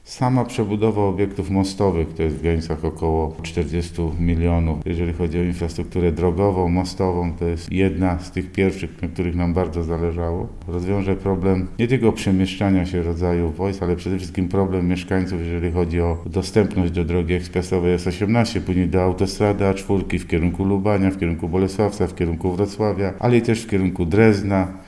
– To jedna z większych i jednocześnie najważniejszych inwestycji – dodaje żagański starosta: